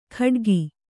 ♪ khaḍgi